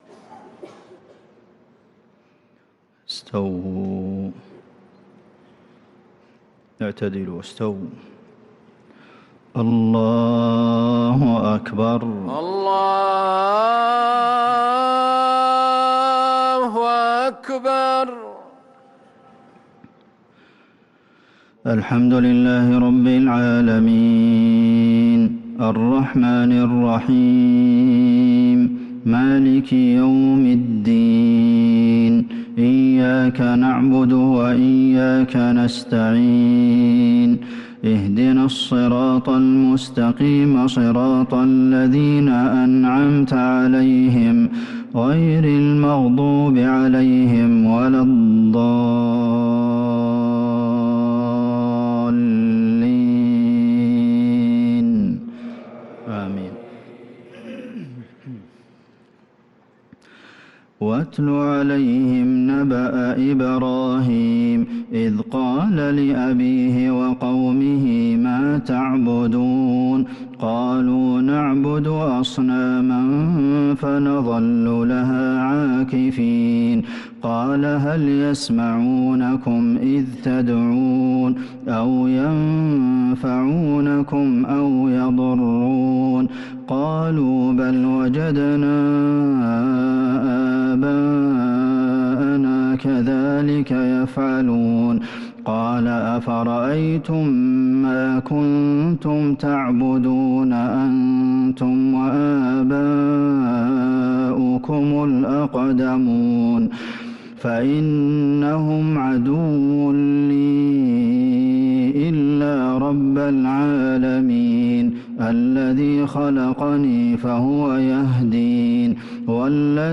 عشاء الجمعة ٥ شوال ١٤٤٣هـ من سورة الشعراء | Isha prayer from surah ash-Shu`ara' r 6-5-2022 > 1443 🕌 > الفروض - تلاوات الحرمين